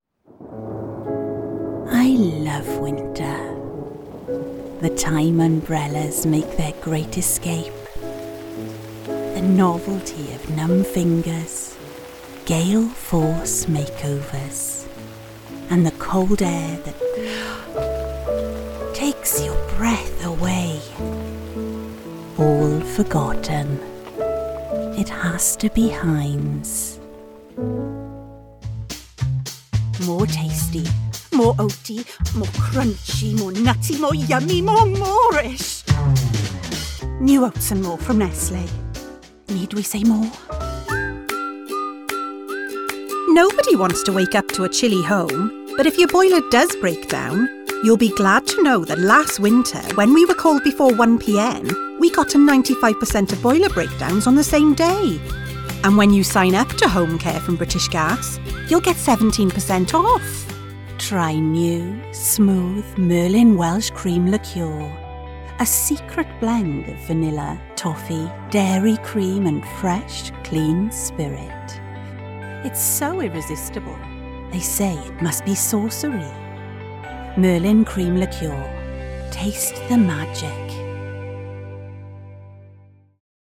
Welsh Accent Commercial Showreel
Female
Friendly
Warm